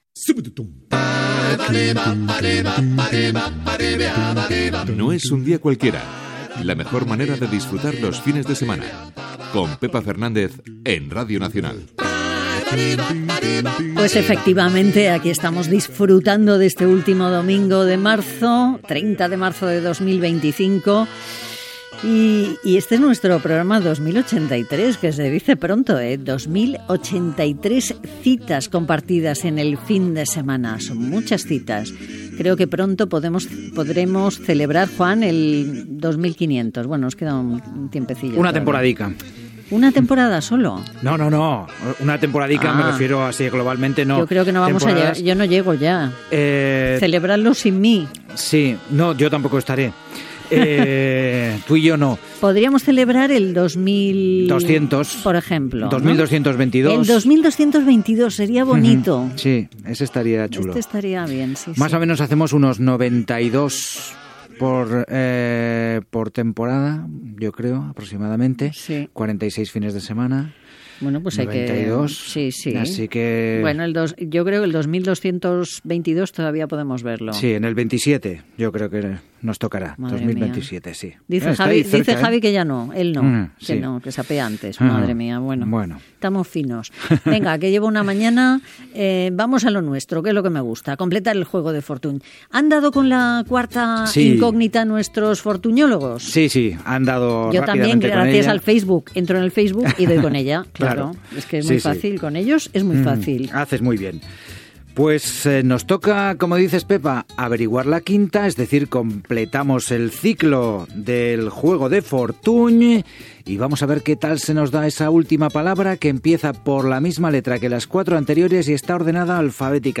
Indicatiu del programa, conversa sobre el número de programes emesos (2083) i concurs de paraules de "Fortuny".
Entreteniment
FM